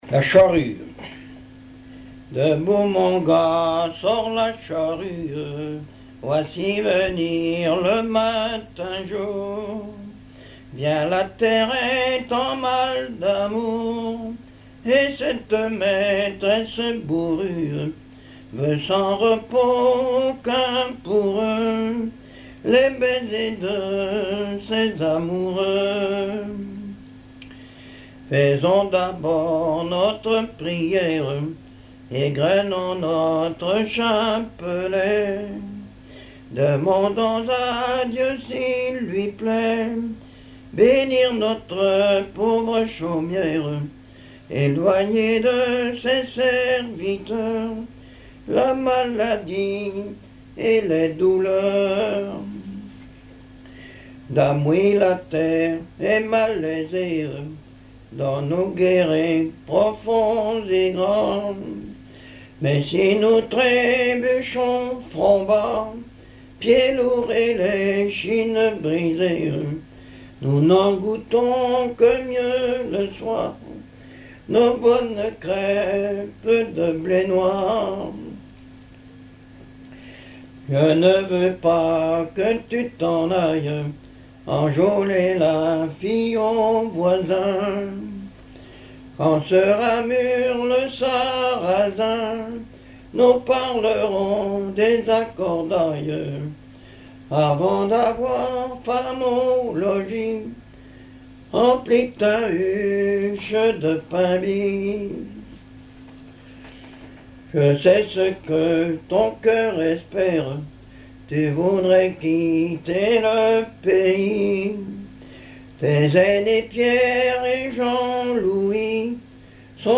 contes, récits et chansons populaires
Pièce musicale inédite